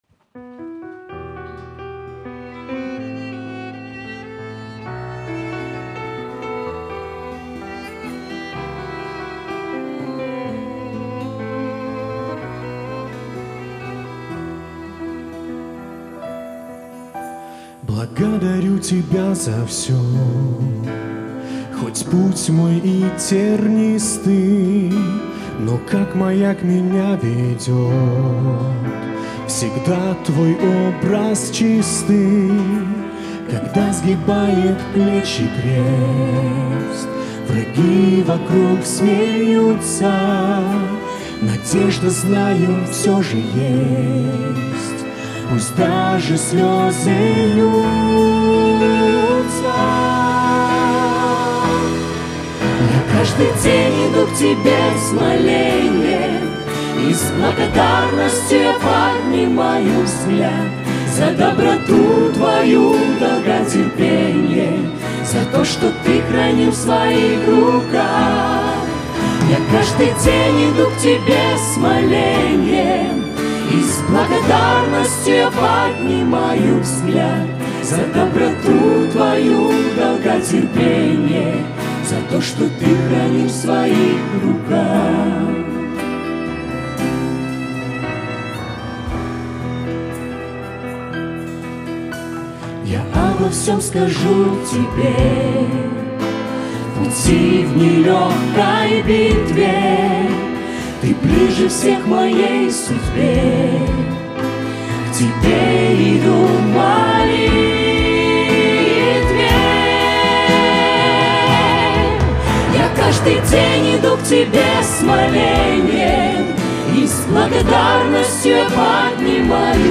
гитара
ударные
клавиши, вокал
вокал